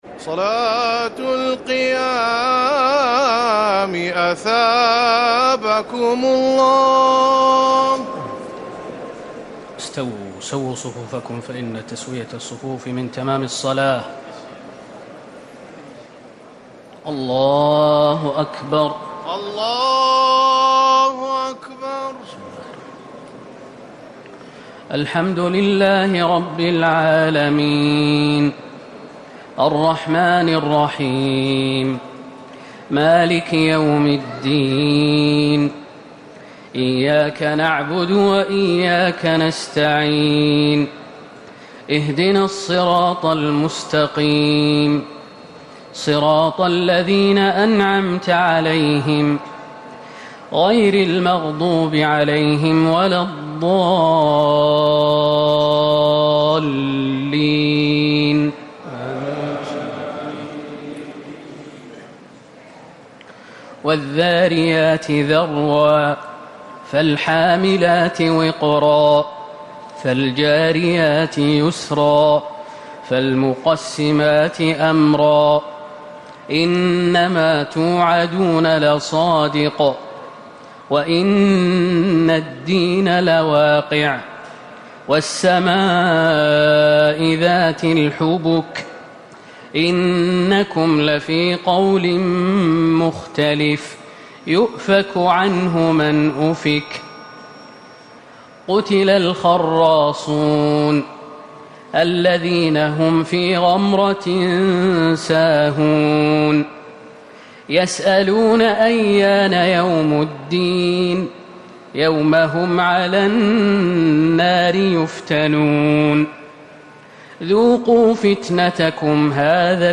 تراويح ٢٦ رمضان ١٤٤٠ من سورة الذاريات - القمر > تراويح الحرم النبوي عام 1440 🕌 > التراويح - تلاوات الحرمين